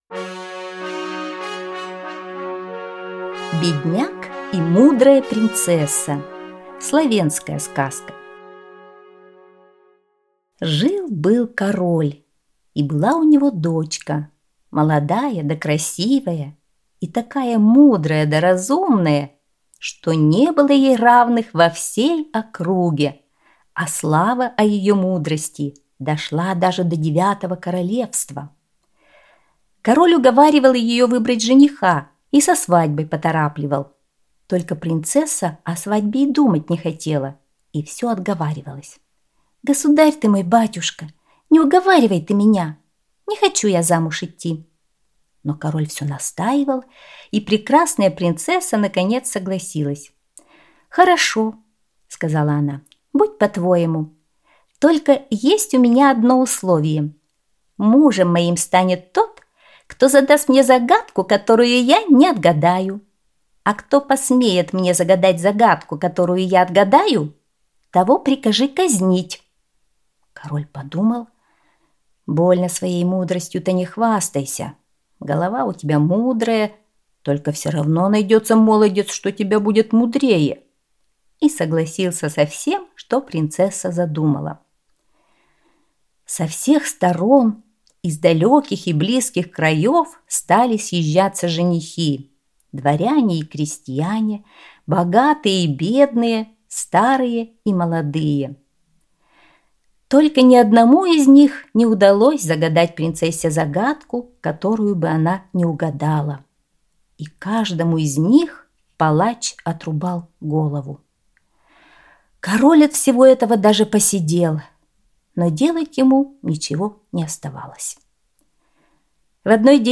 Словенская аудиосказка